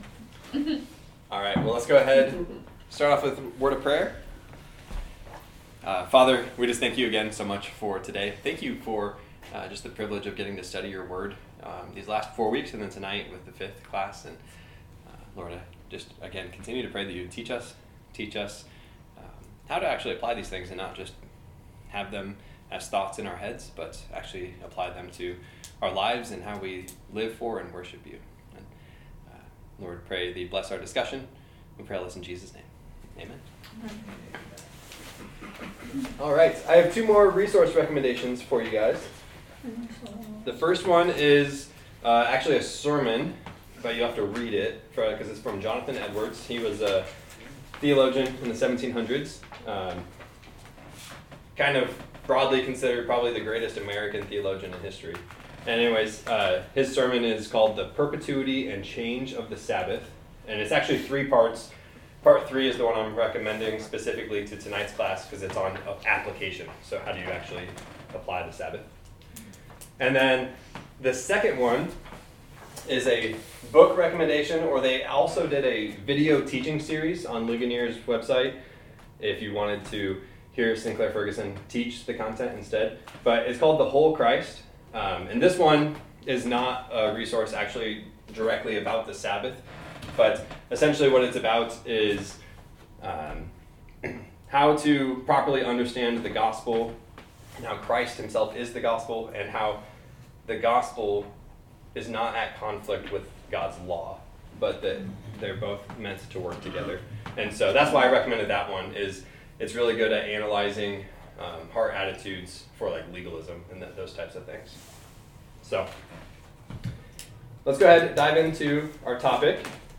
Meaning the audio is recorded from a mic that picks up the whole room and has only received a minimum amount of editing afterwards.